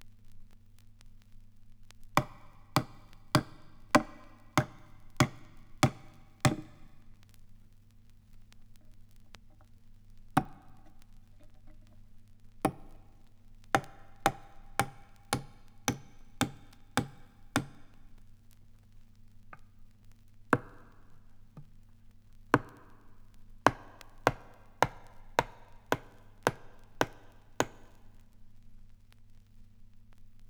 • nailing coffin lid down - hammer hits.wav
Recorded from Sound Effects - Death and Horror rare BBC records and tapes vinyl, vol. 13, 1977.
nailing_coffin_lid_down_-_hammer_hits_3Co.wav